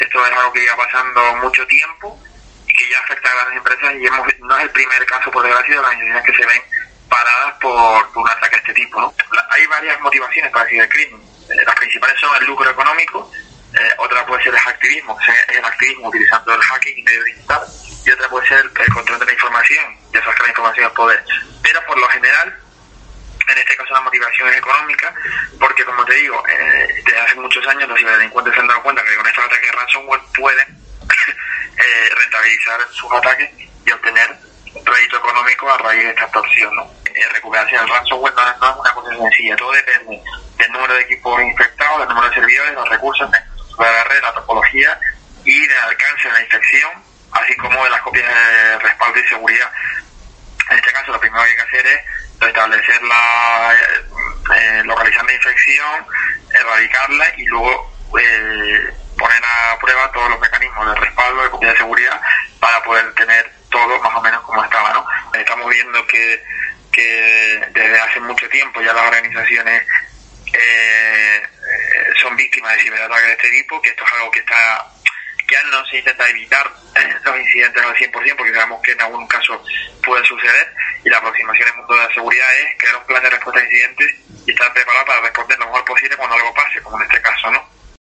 experto en ciberseguridad